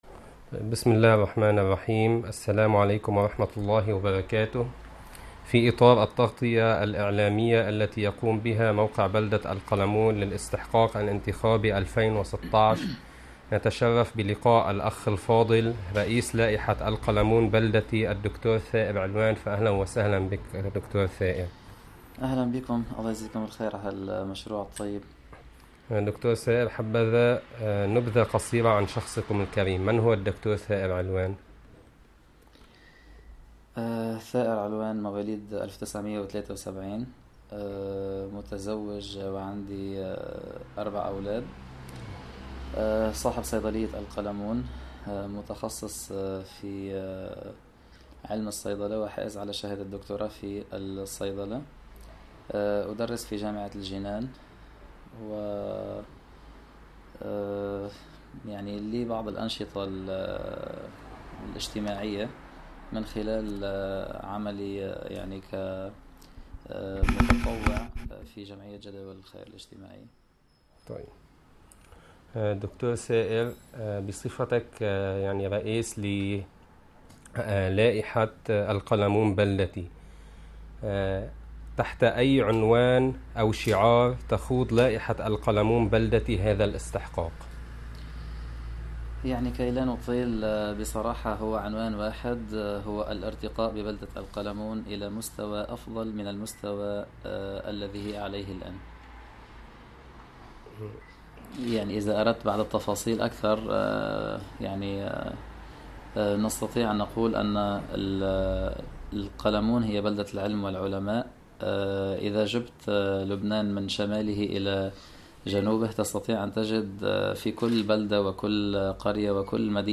فيما يلي المقابلة الصوتية …